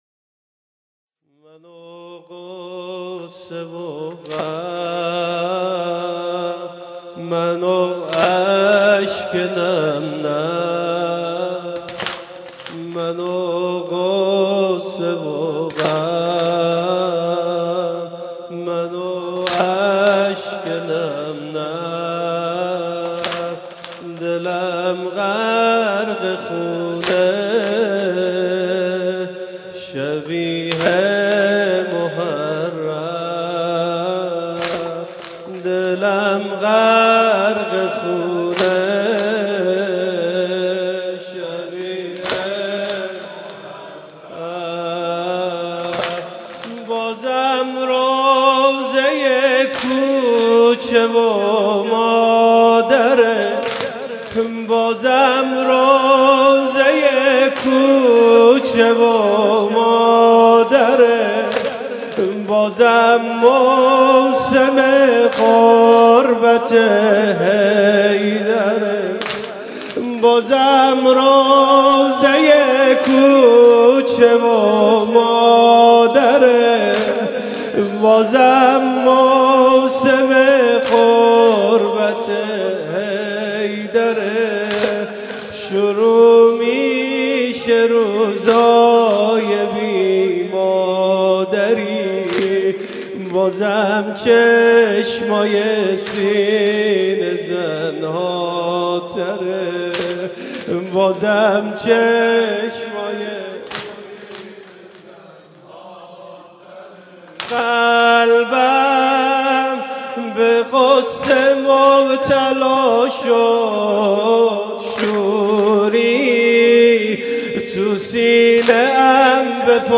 9 بهمن 96 - هیئت شبان القاسم - واحد - وای مادر مظلومه من